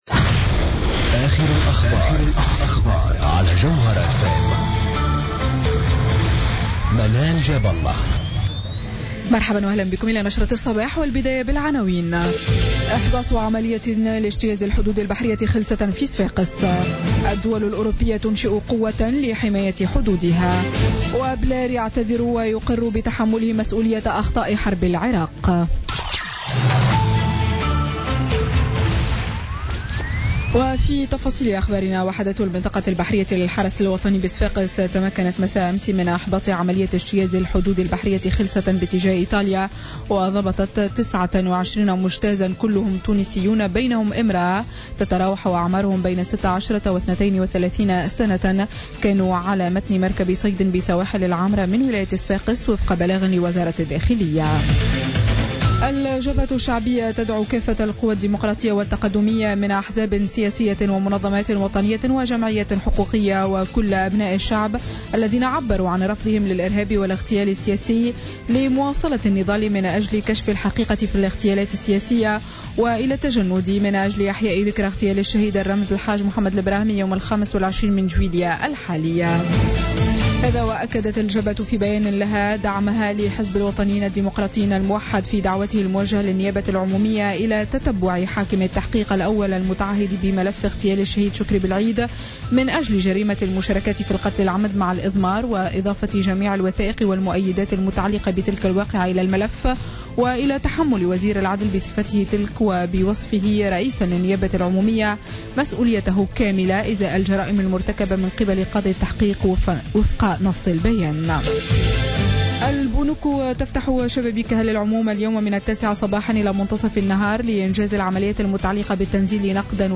Journal Info 07h00 du jeudi 7 juillet 2016